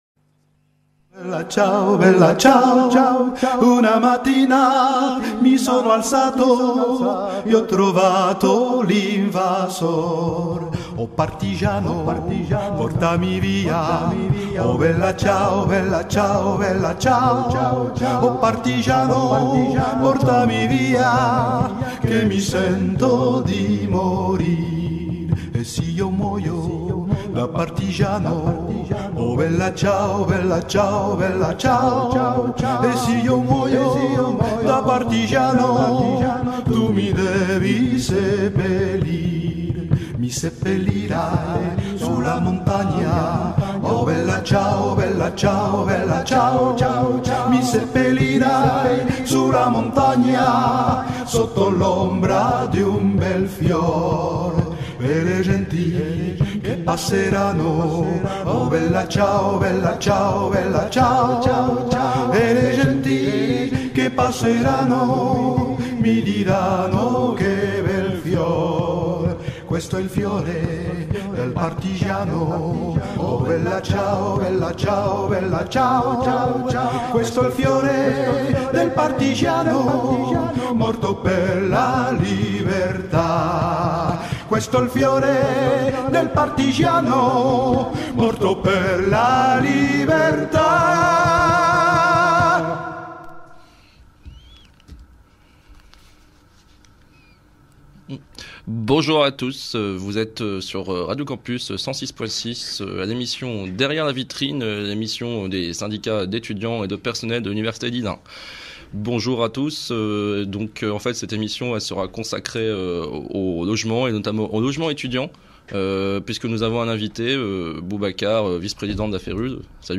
« Derrière la Vitrine », c’est l’émission des syndicats (étudiant-e-s et personnels) de l’université Lille1, sur Radio Campus Lille (106,6 FM), tous les jeudis, de 14h à 15h.